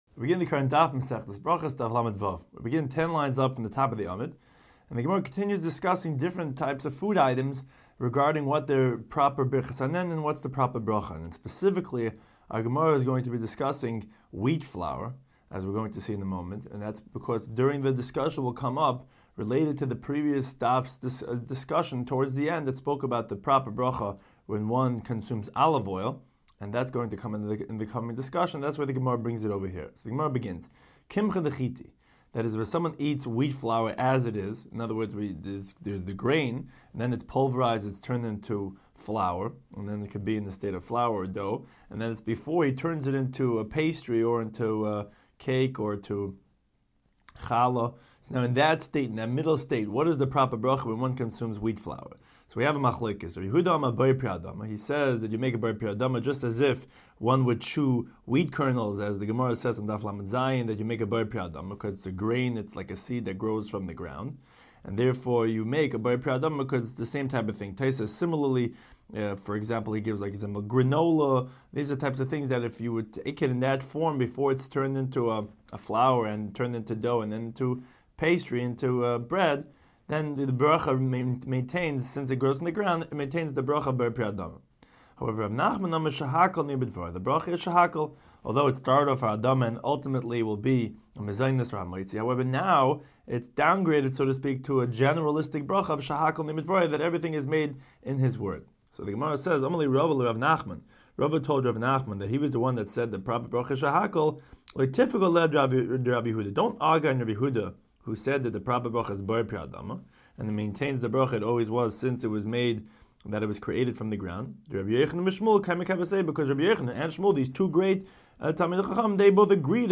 Daf Hachaim Shiur for Berachos 36